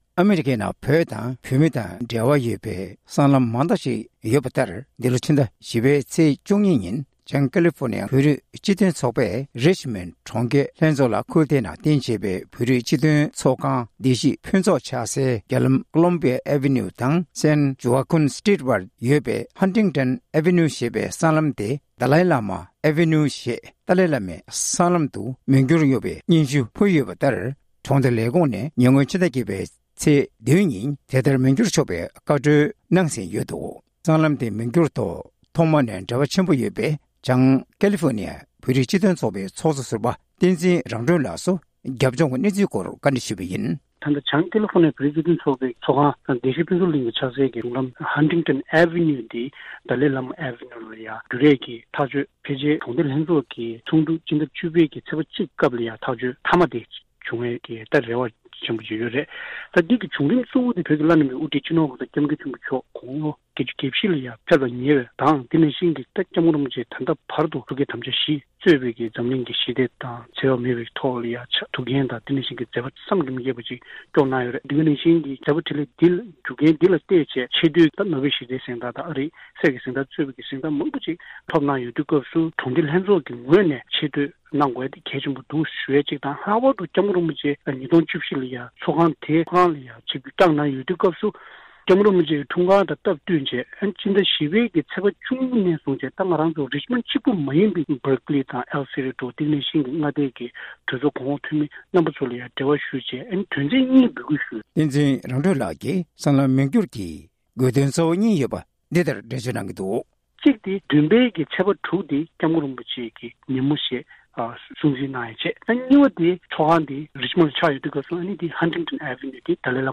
བཀའ་འདྲི་ཞུས་པ་ཞིག་ལ་གསན་རོགས་གནང་